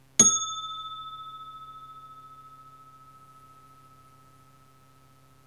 Indian brass pestle
ambience bell brass ding indian mortar music noise sound effect free sound royalty free Music